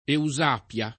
Eusapia [ eu @# p L a ] pers. f.